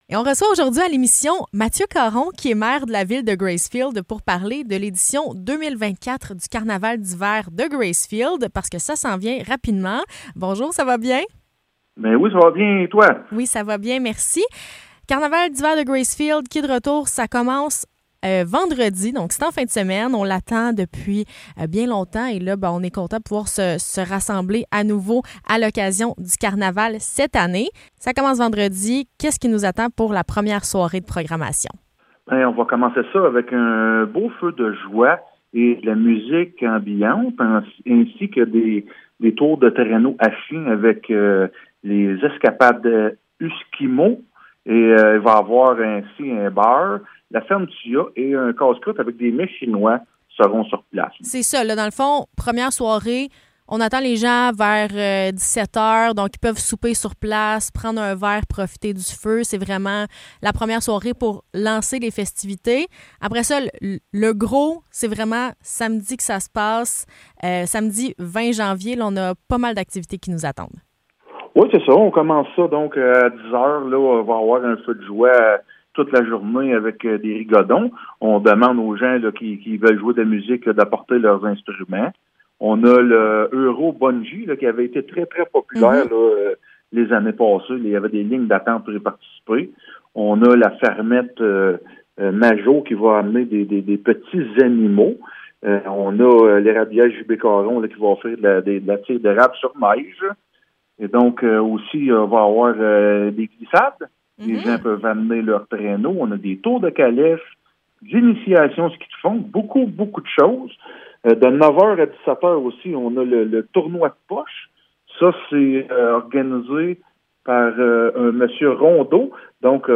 Entrevue avec Mathieu Caron